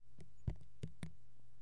水滴在纸上 " 水在纸上13
描述：滴在纸上。